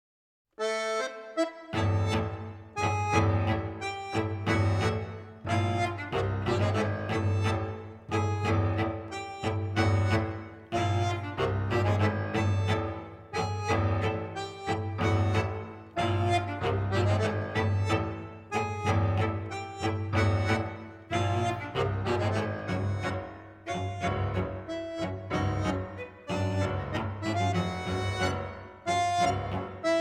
accordion
violin
double bass
piano
percussion